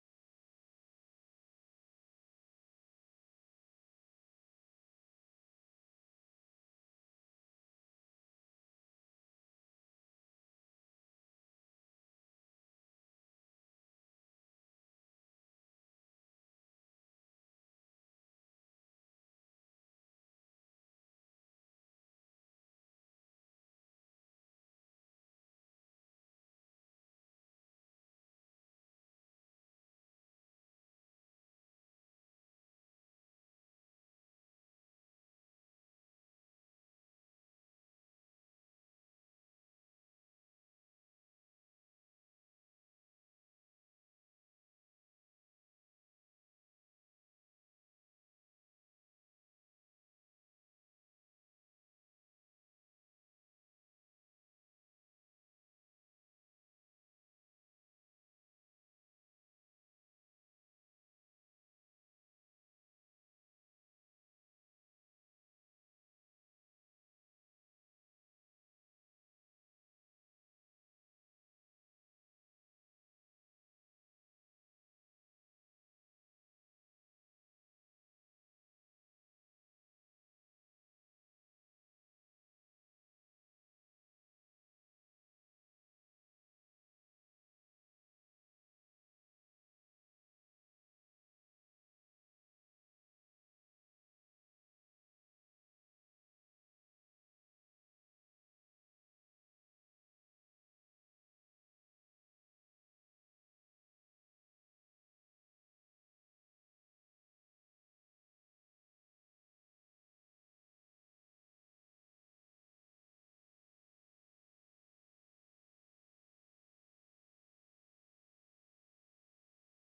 Vivez l'intensité du Jeudi Saint depuis l'abbaye bénédictine de Tournay, entre prière, silence et beauté liturgique